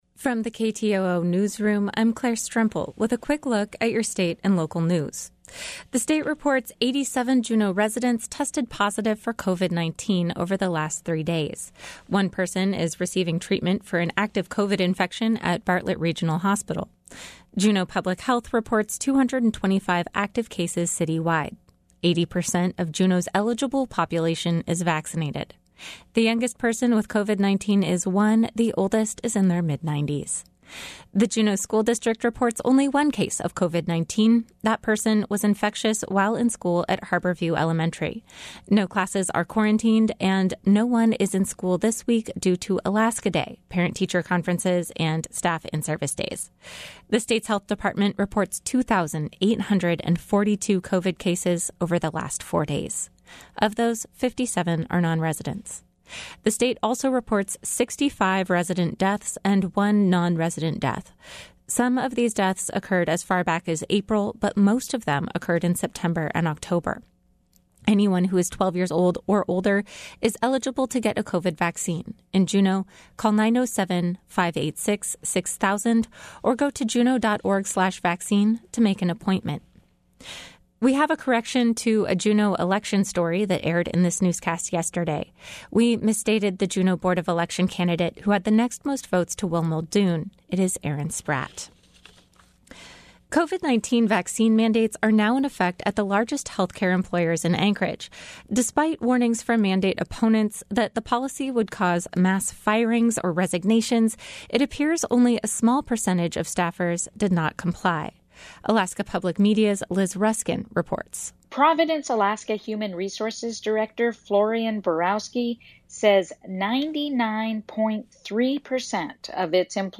Newscast — Tuesday, Oct. 19, 2021